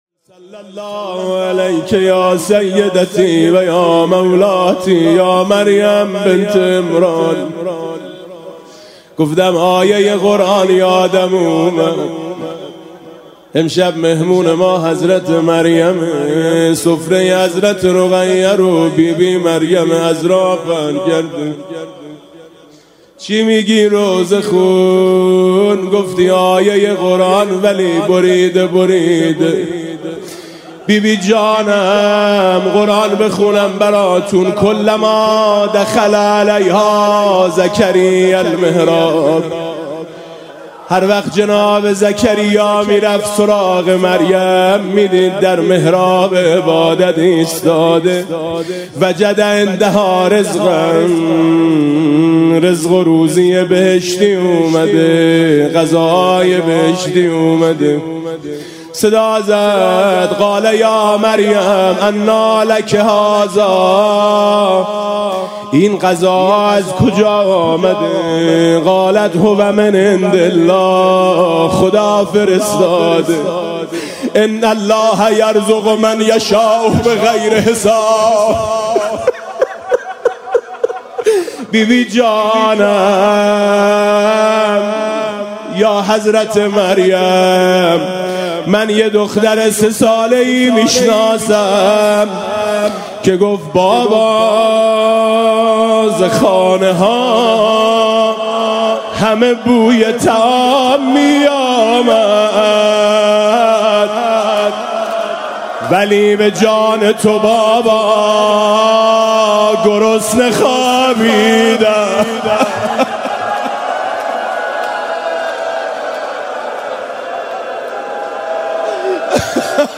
اجرا شده در: شب‌های ماه محرم ۱۴۰۳
هیأت آیین حسینی [مجتمع امام رضا (علیه‌السلام)]